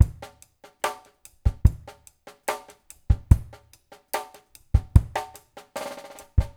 BOL DRUMS 1.wav